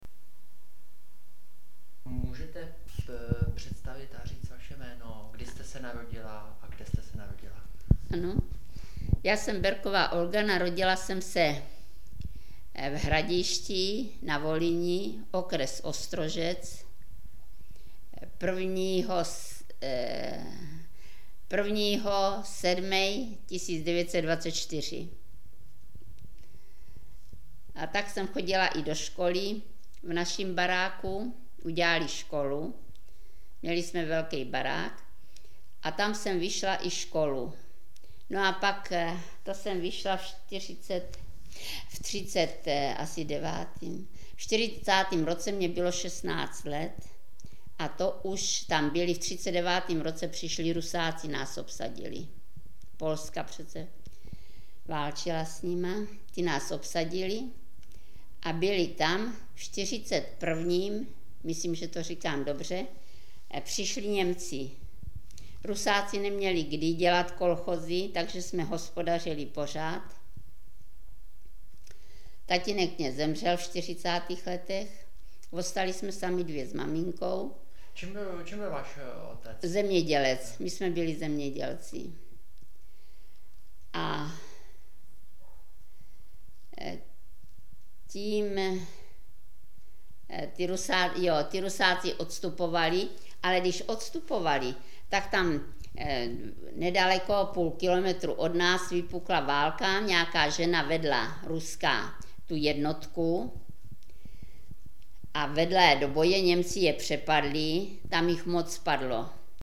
Klip "představení" z vyprávění pamětnice